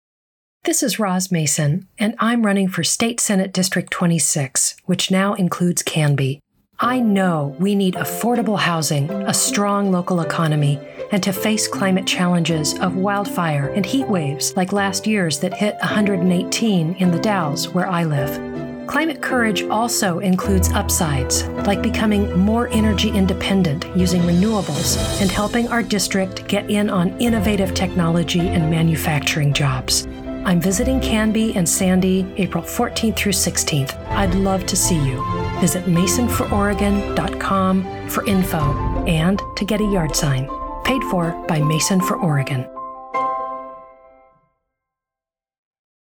Audio Ad – Canby/Sandy Visit 4/14-16